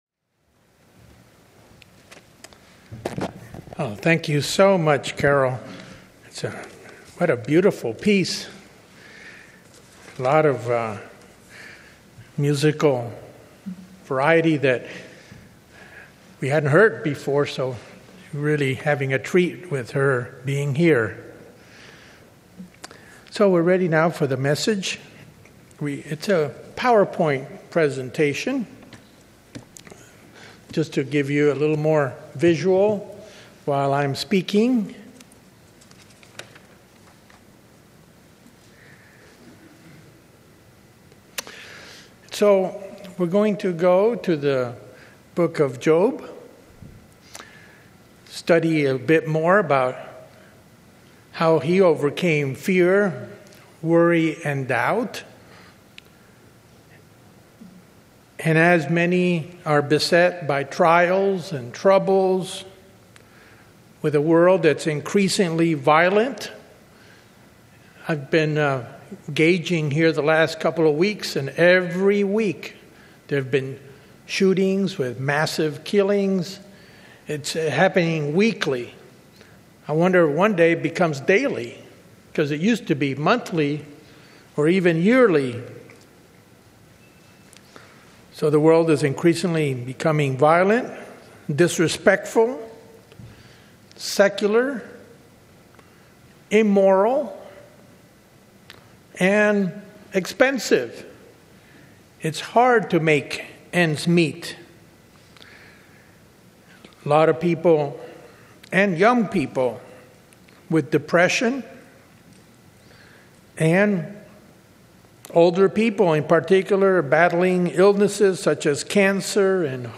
The pastor examines the Book of Job and outlines four principle lessons that will help a member overcome worries, fears, and doubt when facing any trial.